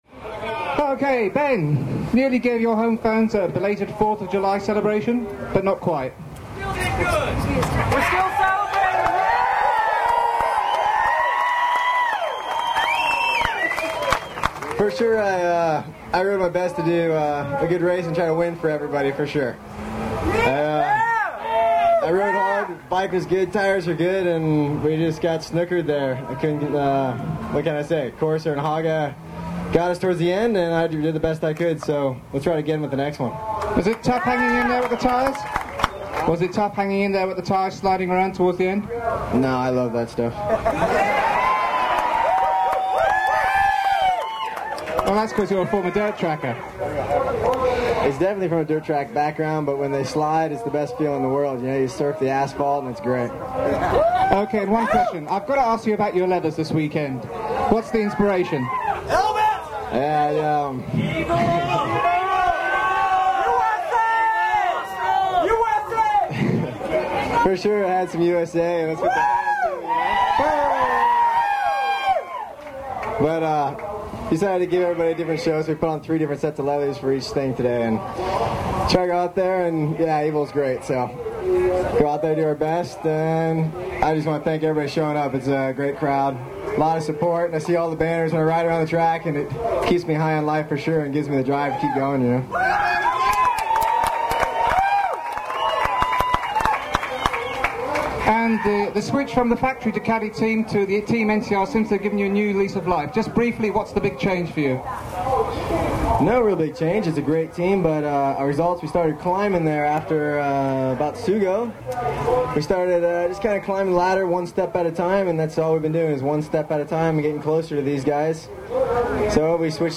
Laguna Seca Interview (Audio Only)
Replete with drunk, screaming hooligans in the background.